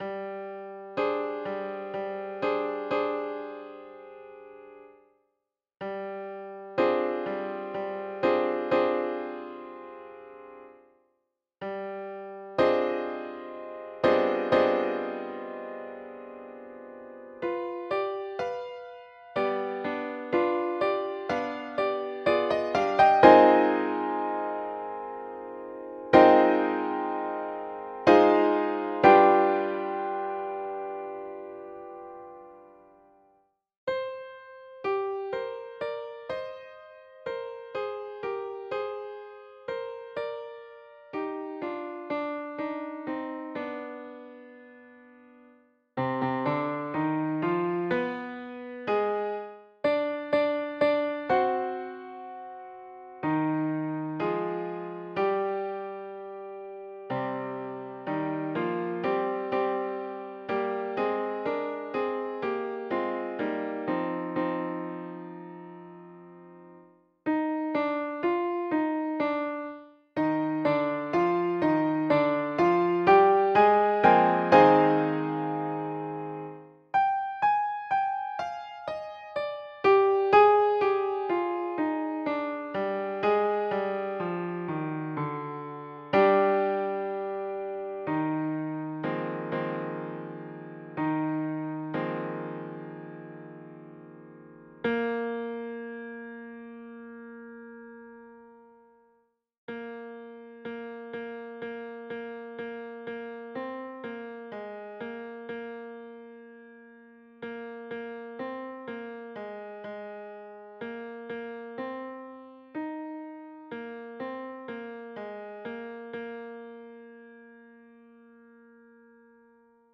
4-part mixed choir, SATB A Cappella
宗教音樂